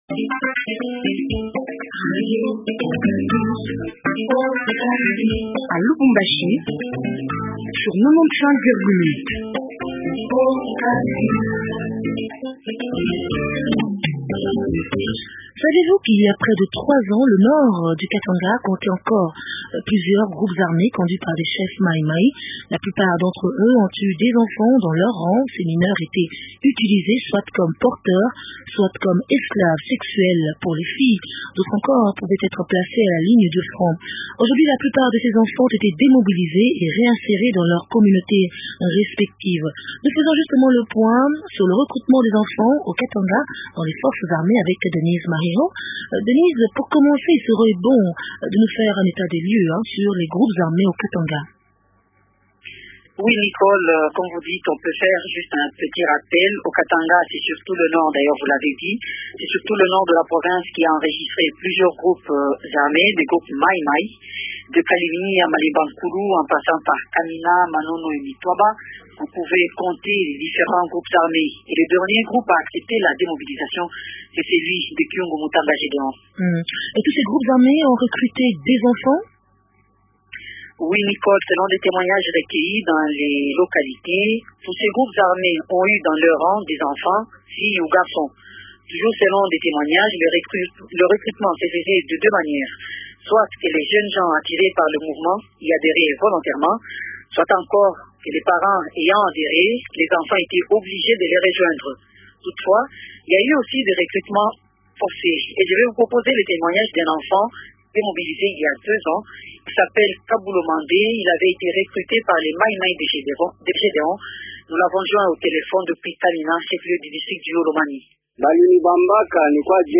Lubumbashi (Rediffusion) : «Chronique Beneveloncja »